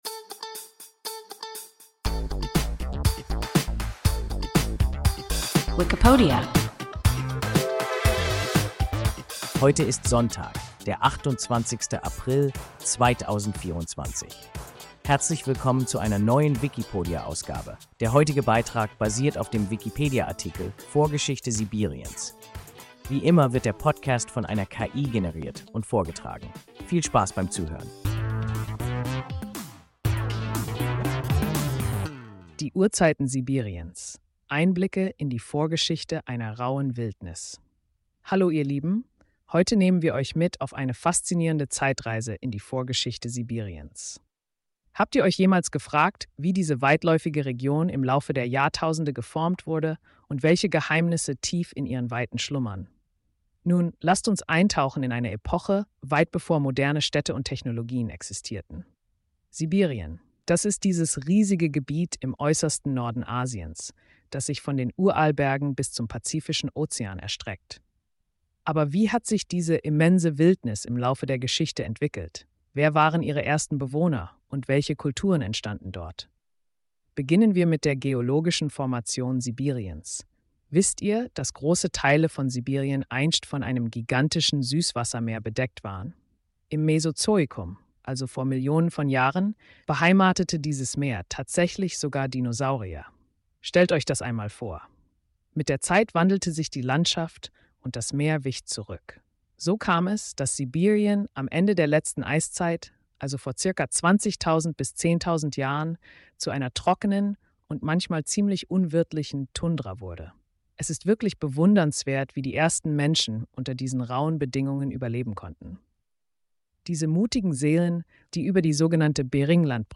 Vorgeschichte Sibiriens – WIKIPODIA – ein KI Podcast